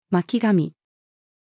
巻き髪-female.mp3